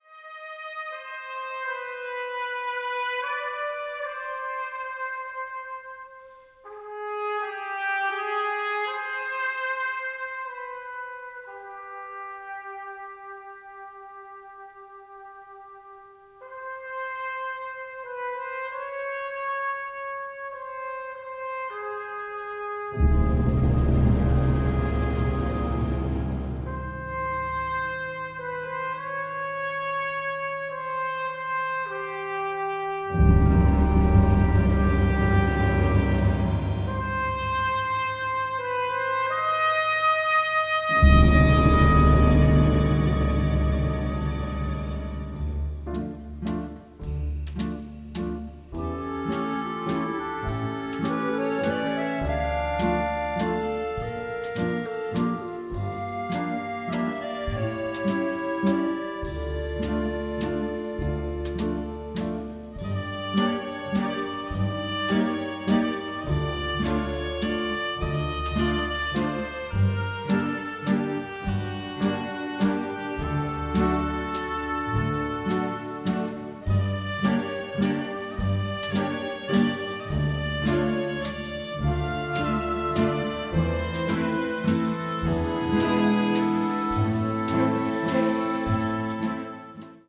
original motion picture score
the score is operatic in its power